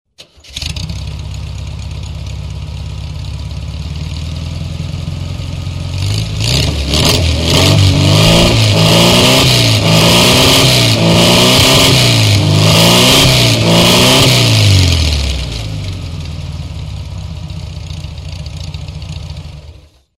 Motor Turbos.mp3